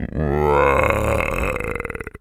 hippo_groan_04.wav